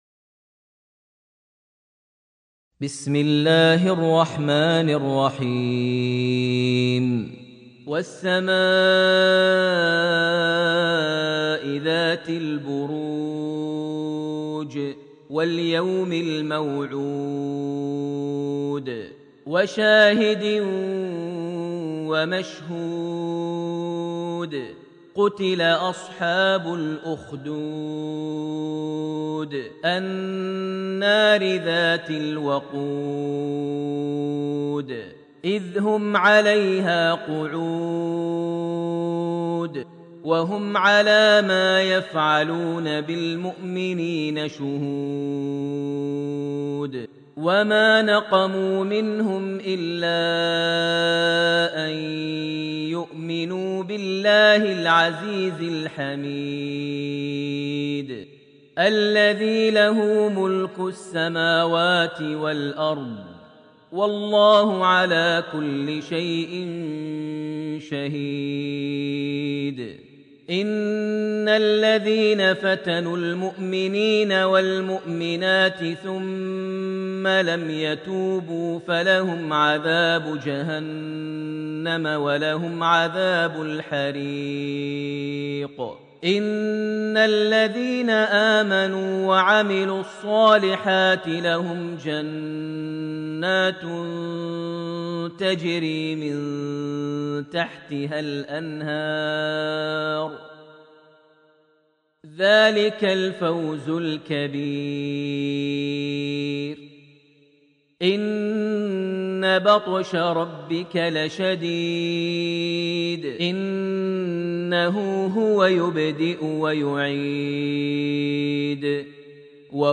سورة البروج > المصحف المجوَّد ( سُجِّلَ في مُجمع الملك فهد لطباعة المصحف ) > المصحف - تلاوات ماهر المعيقلي